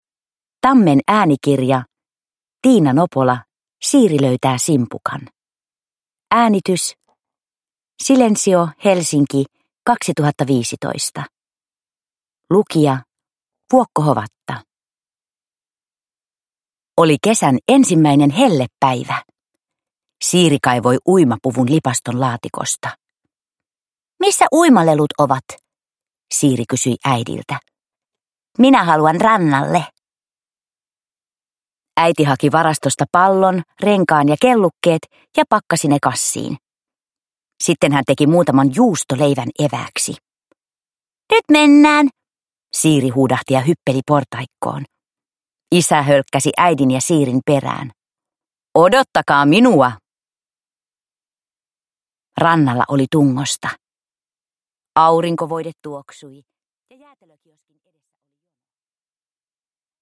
Siiri löytää simpukan – Ljudbok – Laddas ner